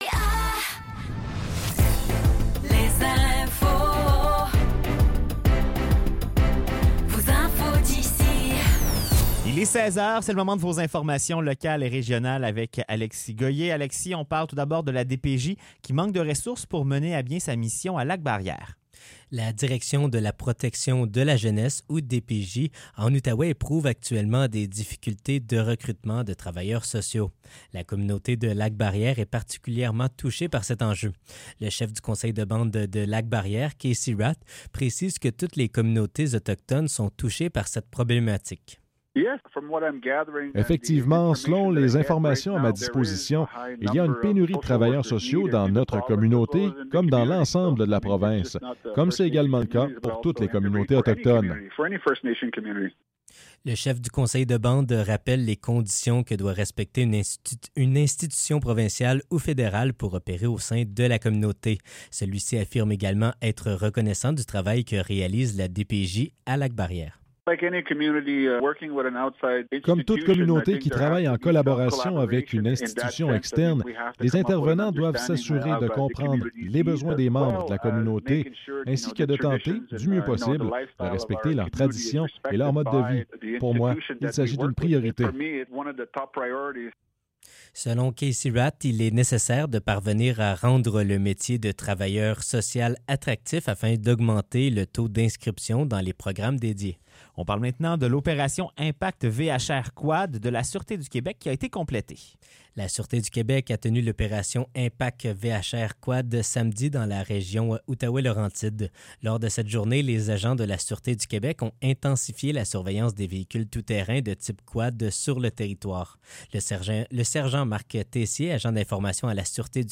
Nouvelles locales - 19 juin 2024 - 16 h